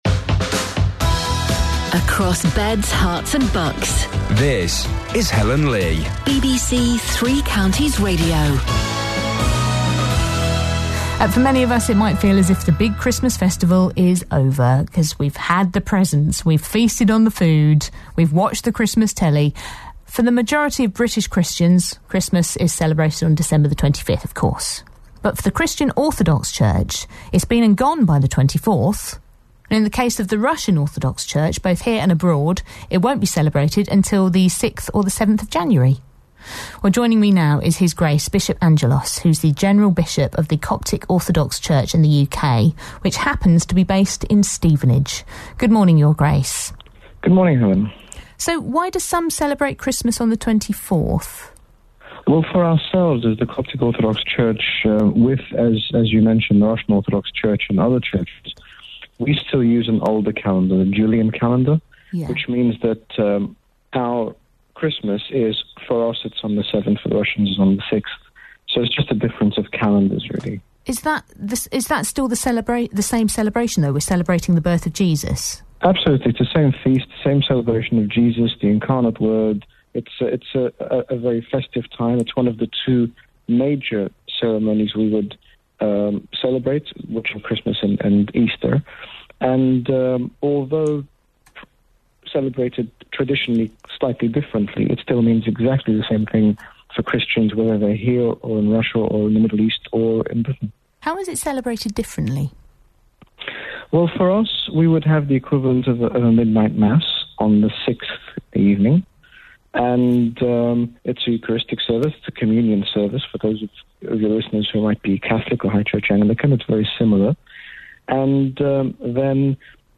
BBC 3 Counties Radio Interview with HG Bishop Angaelos re Orthodox Christmas
BBC Three Counties Radio interviews His Grace Bishop Angaelos, General Bishop of the Coptic Orthodox Church regarding the Orthodox celebration of Christmas, also touching on the persecution of Christians in the Middle East